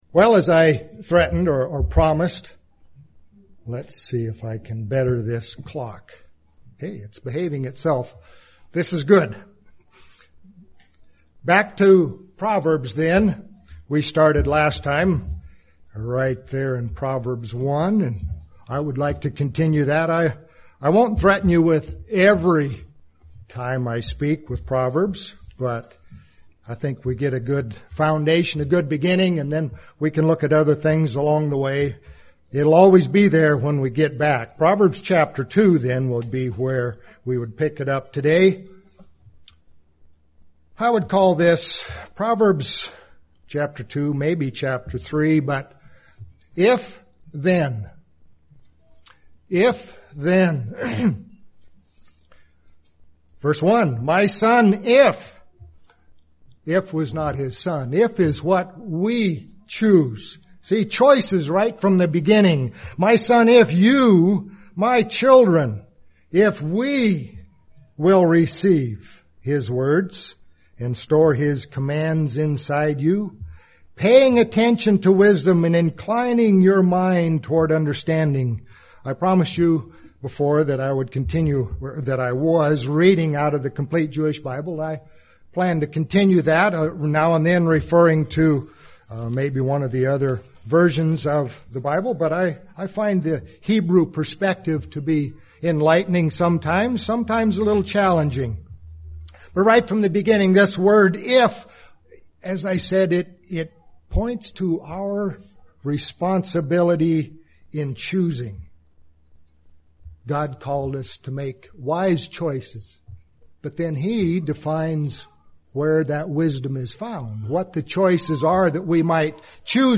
Given in Medford, OR
UCG Sermon Studying the bible?